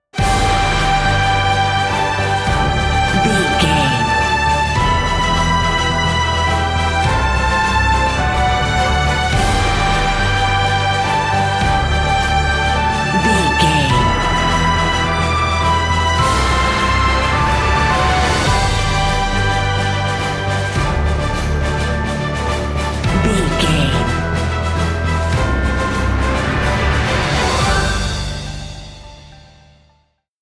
Ionian/Major
energetic
epic
brass
orchestra
piano
strings